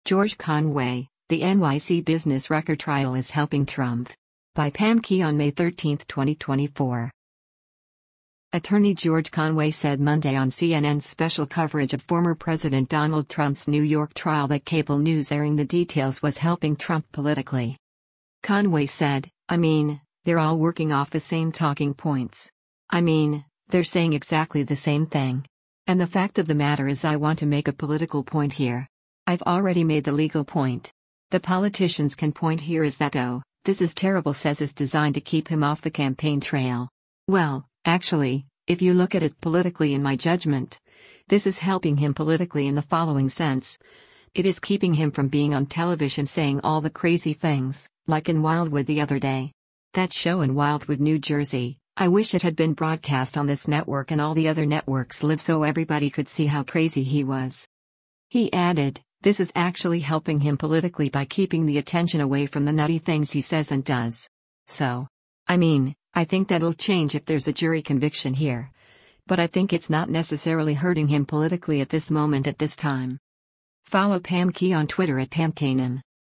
Attorney George Conway said Monday on CNN’s special coverage of former President Donald Trump’s New York trial that cable news airing the details was helping Trump politically.